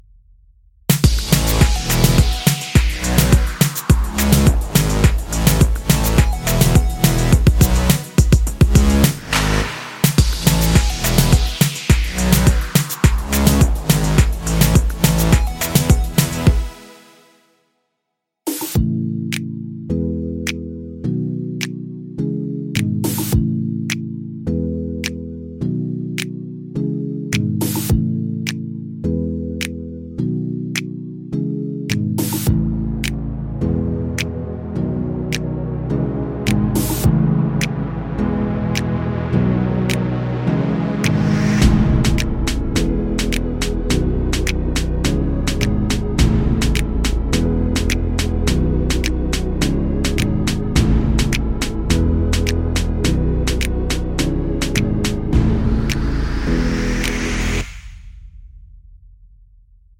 no Backing Vocals At All Pop (2010s) 3:53 Buy £1.50